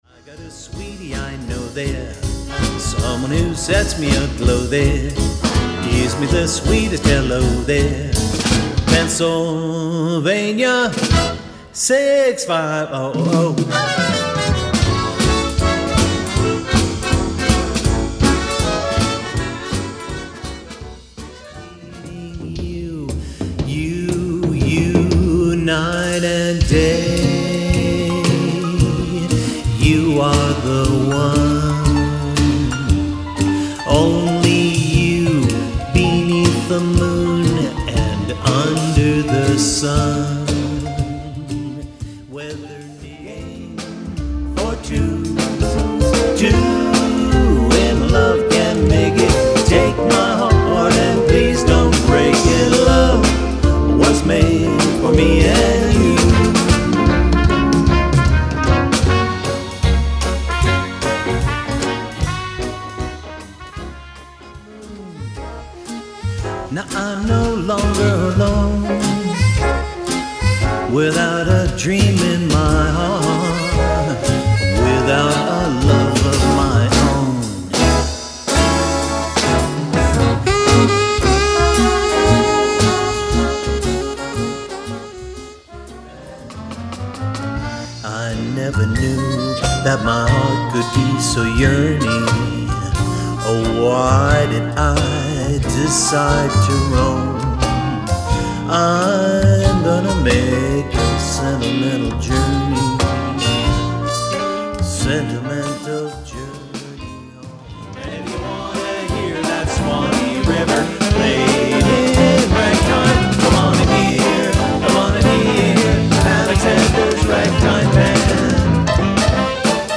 Short promotional selections from across the album.
Big Band – Swing sampler
• One-Man Band (drums, vocals, bass)